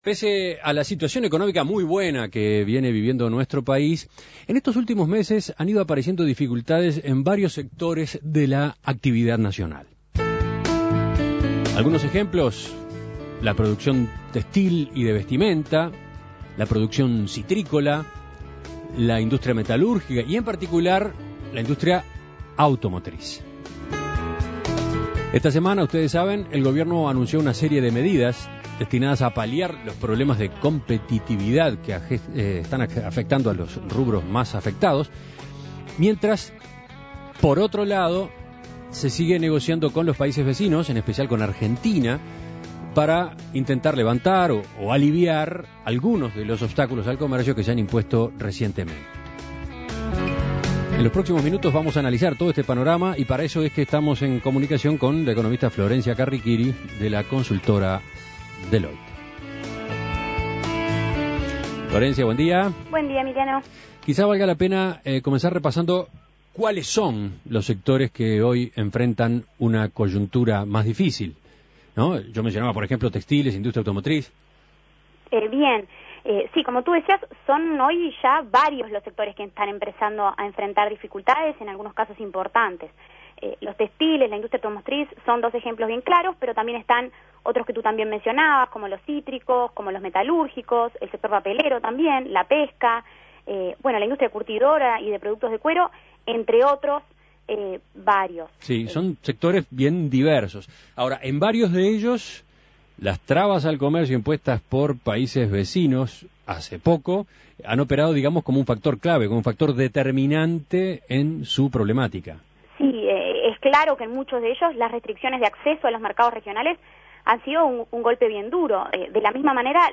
Análisis Económico Las trabas al comercio en la región ponen en jaque a varios sectores de actividad y obligan al Gobierno a tomar medidas.